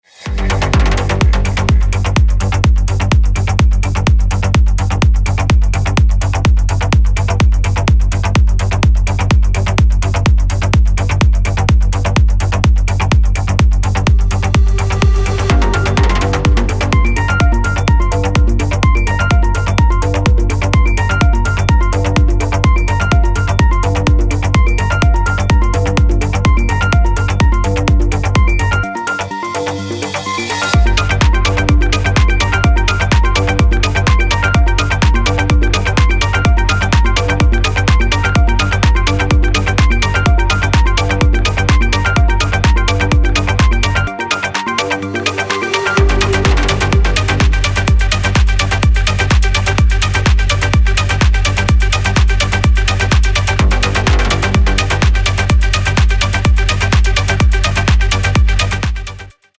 Melodic House & Techno